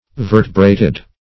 Vertebrate \Ver"te*brate\, Vertebrated \Ver"te*bra`ted\, a. [L.